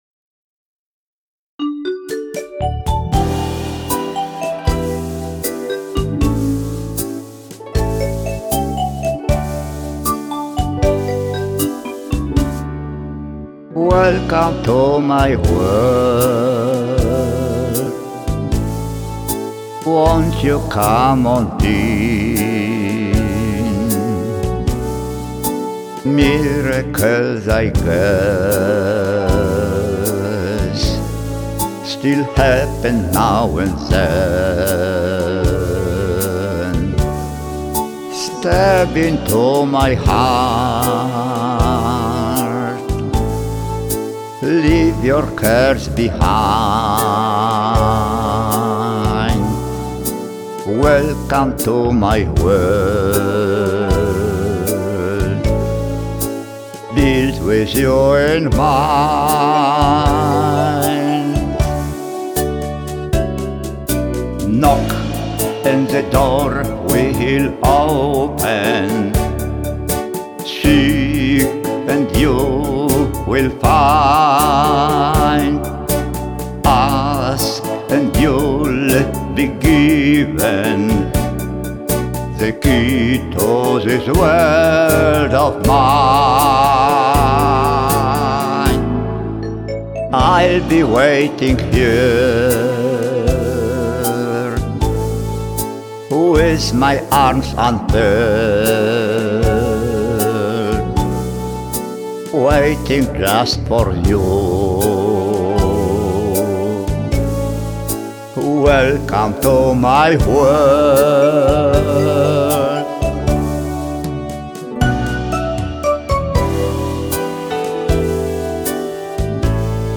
Australijskie country w Czeladzi
australijskimi utworami w stylu country